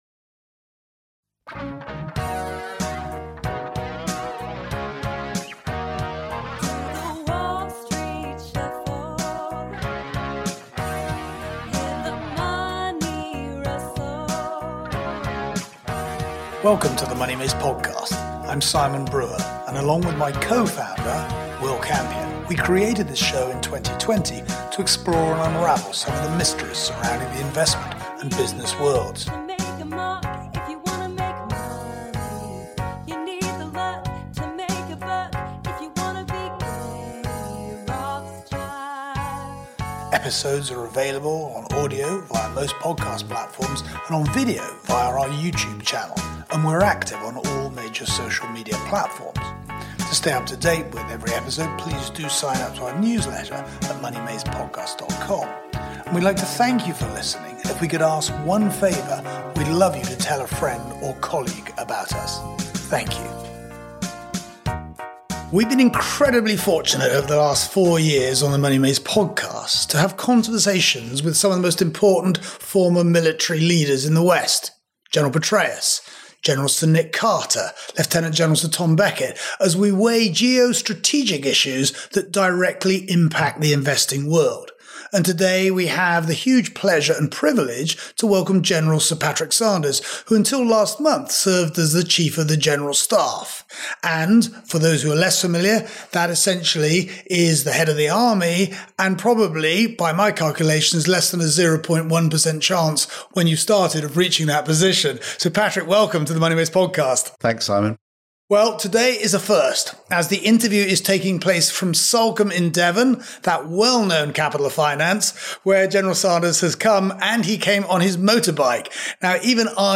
The mission in this interview is to orchestrate a rapid and highly effective tour of the key challenges on the minds of politicians, populations and the planet.